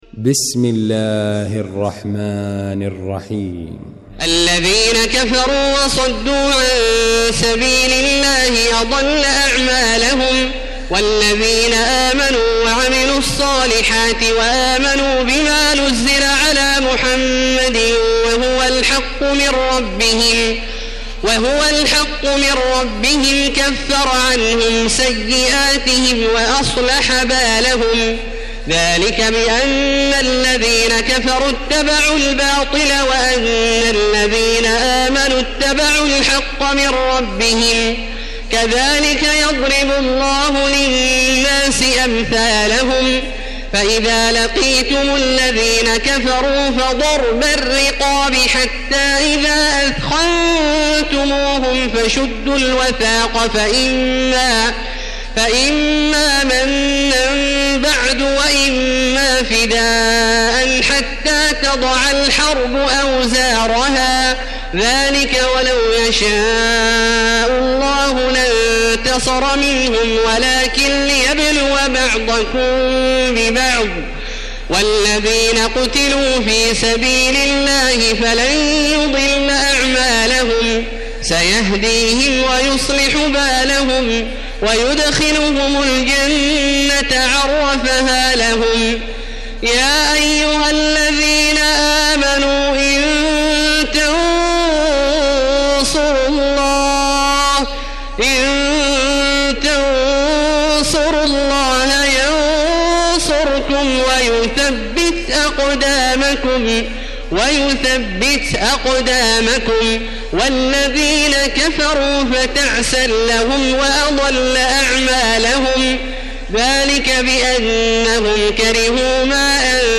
المكان: المسجد الحرام الشيخ: فضيلة الشيخ عبدالله الجهني فضيلة الشيخ عبدالله الجهني محمد